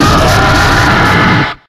Audio / SE / Cries / RAMPARDOS.ogg